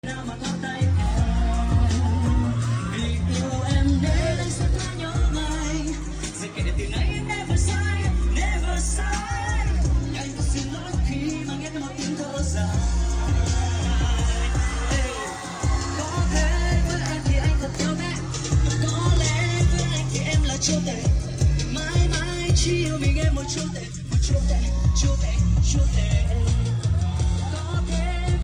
Ủa này là hát live thật ạ👀